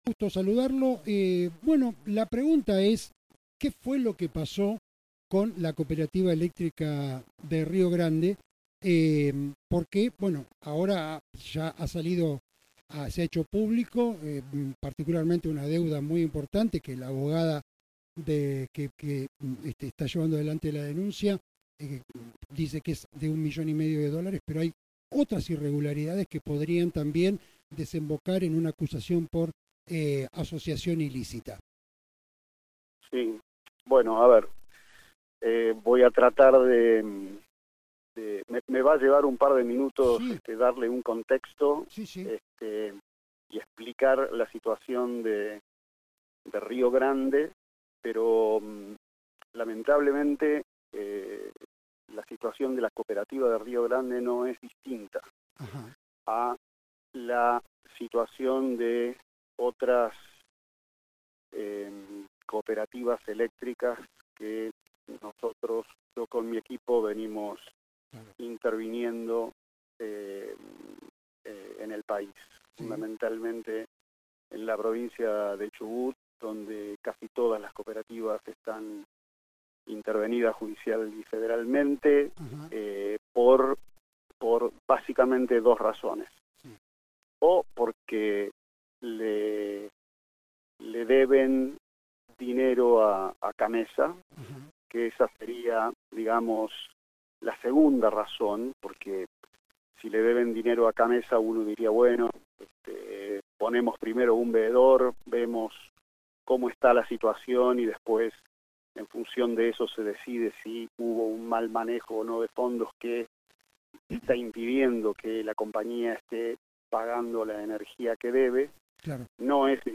En una extensa entrevista, con Resumen Económico